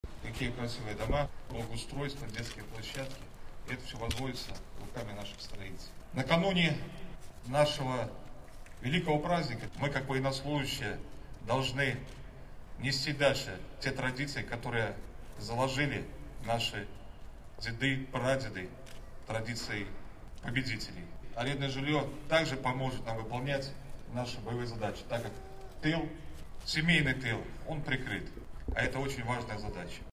В Барановичах сегодня ключи от служебных квадратов получили 34 семьи.
Решение жилищного вопроса всегда было приоритетной задачей руководства нашего государства, — подчеркнул генерал-майор Андрей Бурдыко.